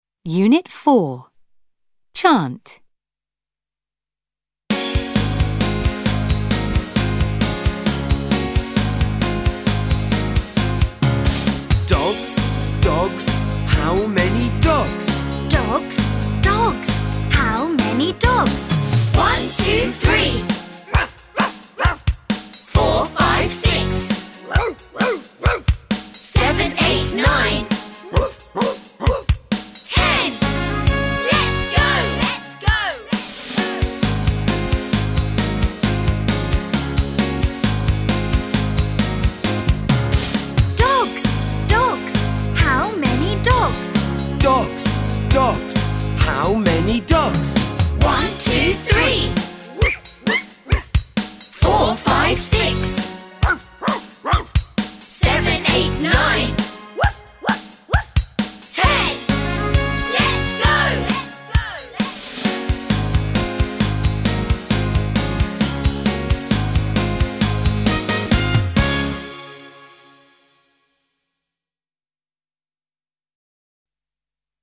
Utrwalaliśmy znaną nam rymowankę językową ilustrowaną ruchem ,,How many dogs ?”